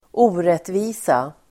Uttal: [²'o:retvi:sa]